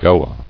[Go·a]